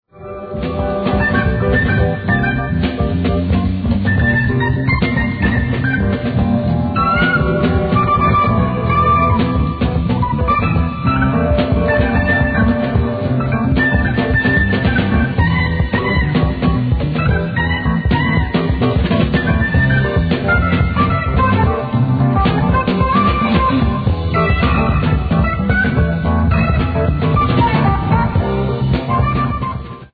Recorded: Autumn 1975 Dierks Studio - Stommeln Germany
Genre: Jazz/Rock
a light jazz rock sound
simultaneous guitar and keyboard solos
Bass